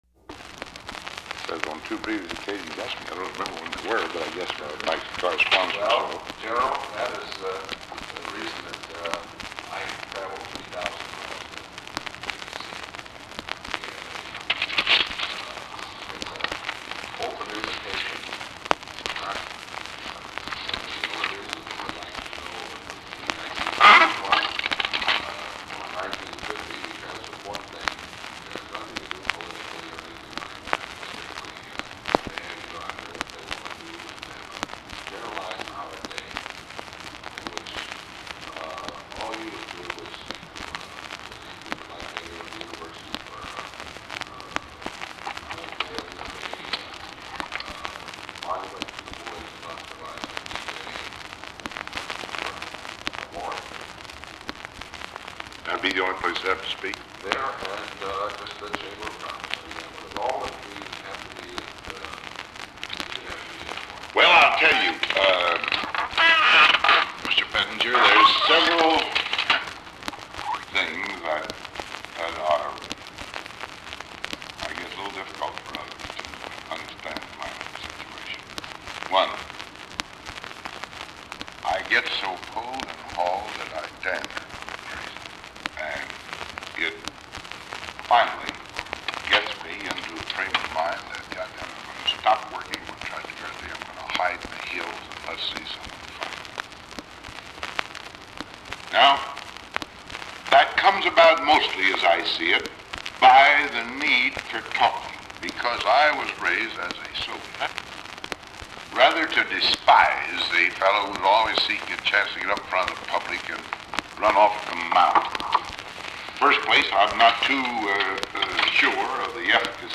Secret White House Tapes | Dwight D. Eisenhower Presidency